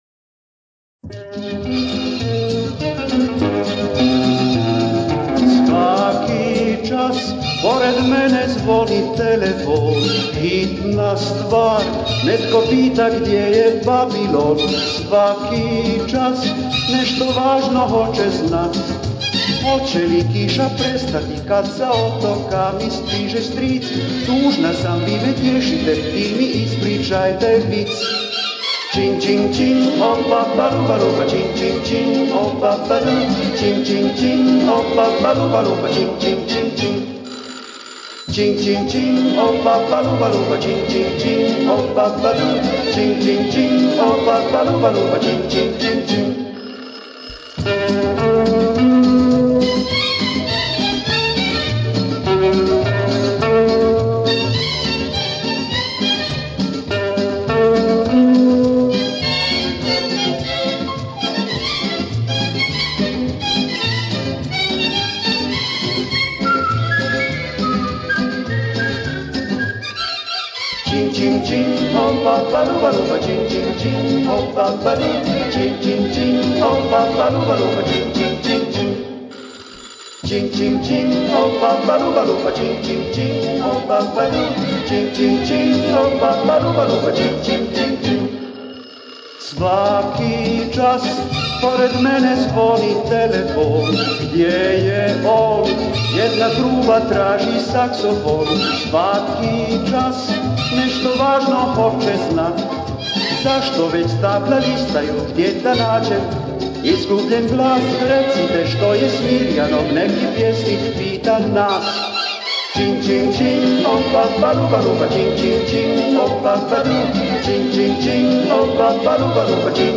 Записал 4 песни с пластиночки.